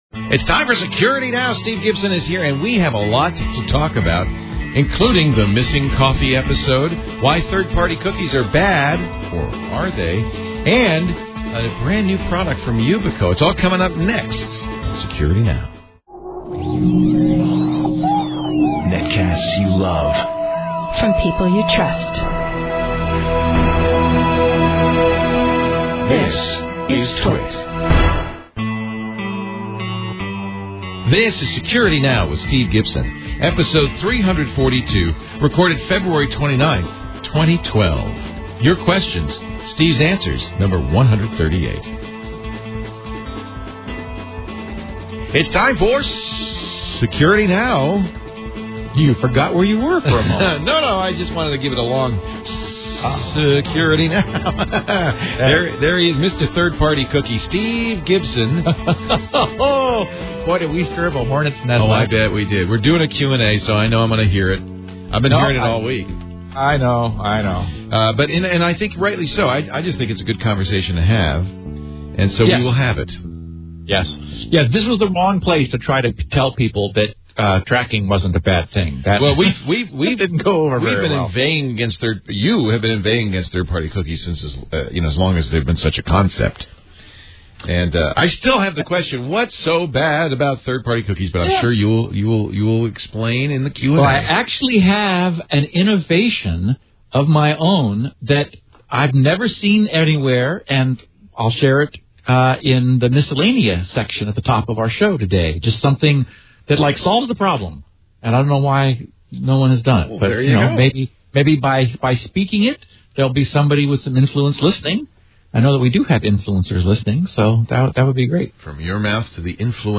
Description: Steve and Leo discuss the week's major security events and discuss questions and comments from listeners of previous episodes. They tie up loose ends, explore a wide range of topics that are too small to fill their own episode, clarify any confusion from previous installments, and present real world 'application notes' for any of the security technologies and issues we have previously discussed.